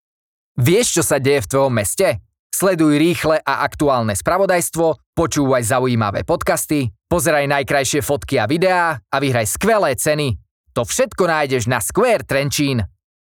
Hlas do reklamy (voiceover)
(vyčistenie nahrávky od nádychov rôznych ruchov a zvuková postprodukcia je samozrejmosťou)